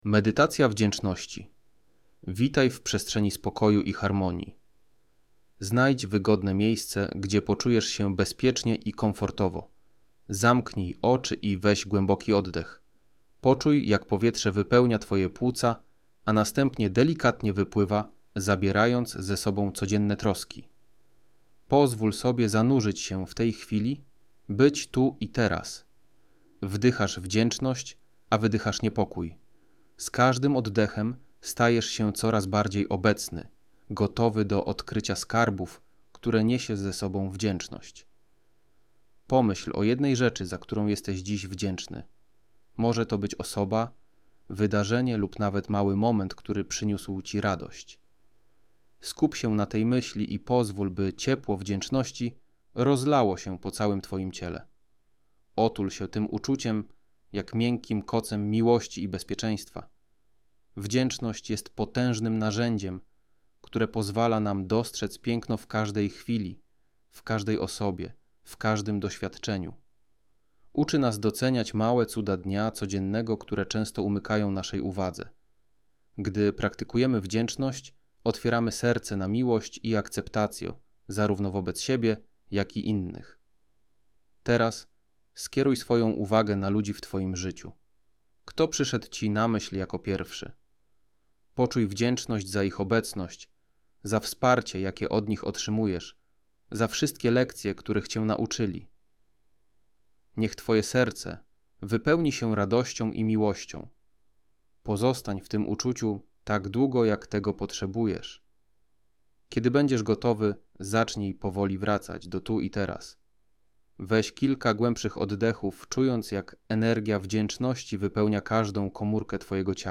Medytacja Wdzięczności to wyjątkowe, delikatne i skuteczne nagranie prowadzone, stworzone z myślą o osobach, które pragną pogłębić swoją praktykę uważności i codzienną refleksję nad dobrymi aspektami życia.
• Audiobook MP3 z profesjonalnie prowadzoną medytacją,